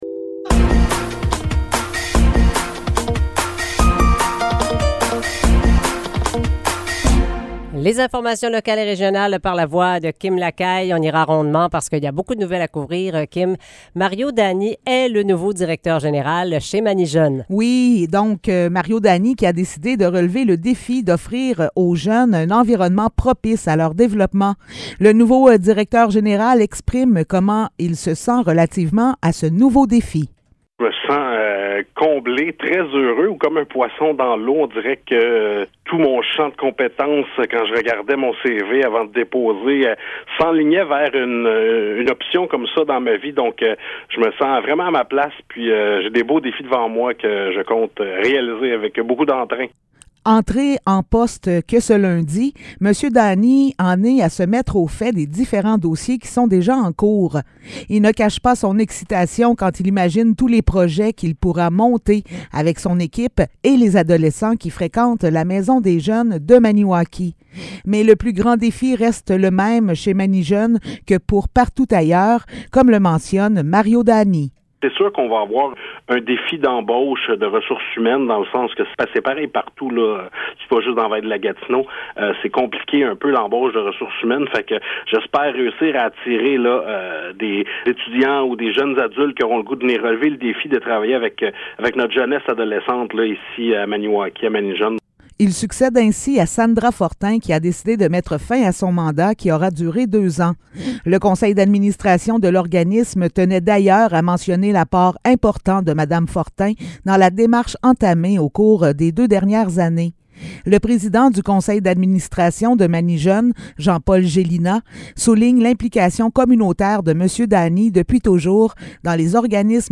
Nouvelles locales - 20 avril 2023 - 8 h